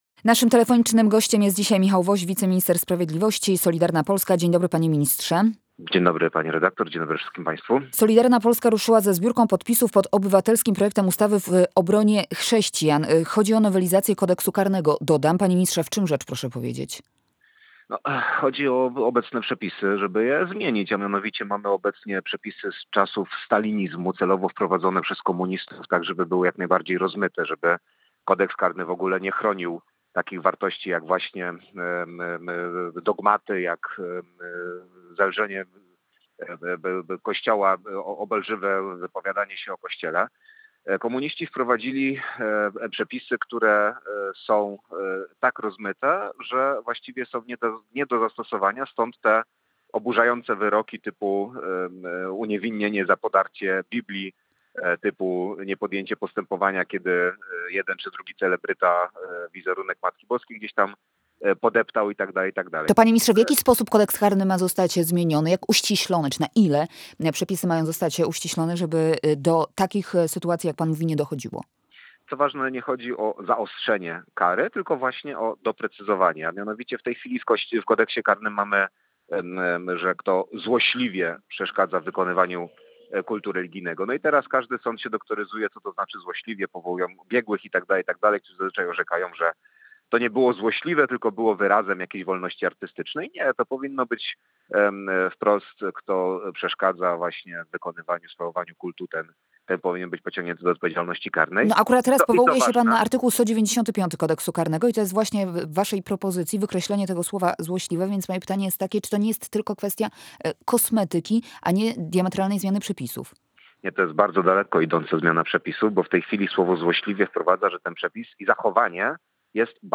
Nie mówimy o zaostrzeniu przepisów, ale ich doprecyzowaniu – mówił na antenie Radia Doxa Michał Woś, wiceminister sprawiedliwości, polityk Solidarnej Polski.